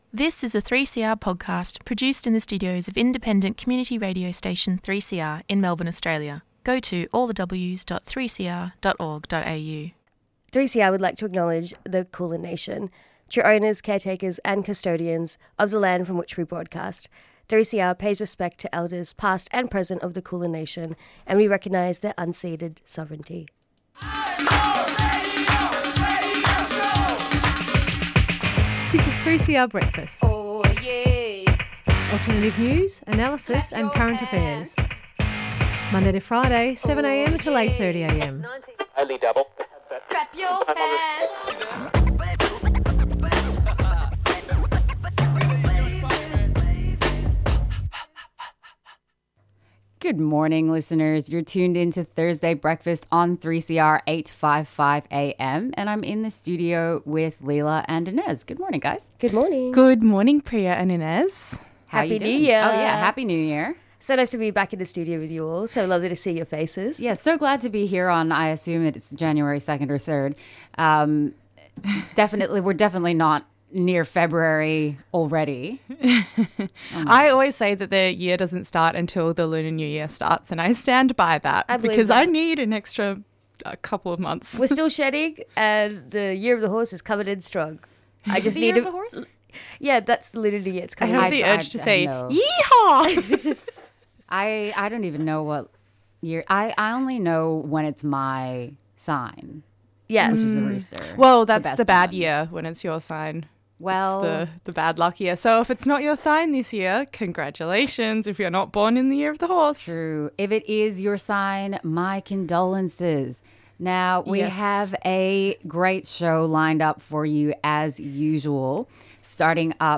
We also hear a short clip of some school girls in Susiya singing the song 'Aytuna Tafola' ('Give Us Our Childhood').